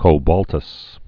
(kō-bôltəs)